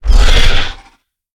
growl2.ogg